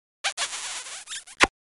Play, download and share lanzar beso original sound button!!!!
lanzar-beso.mp3